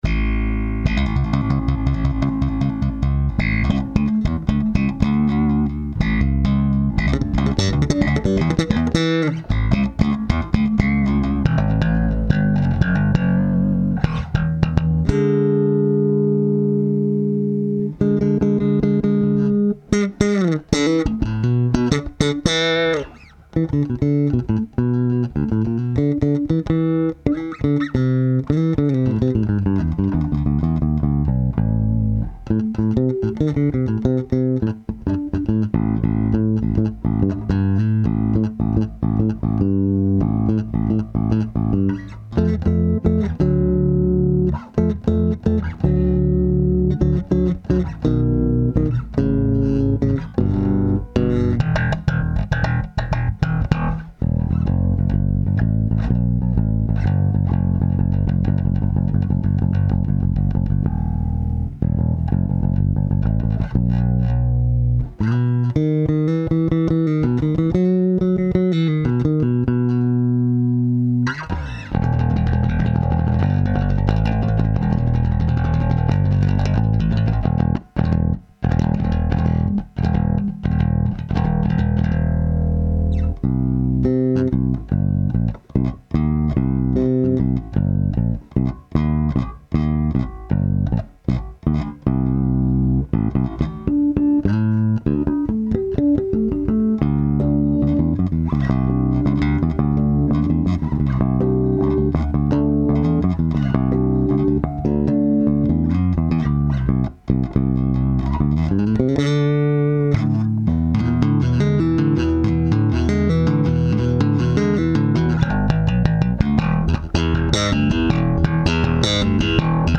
Vigier Excess sound test 7,5 ��
vigier_excess.mp3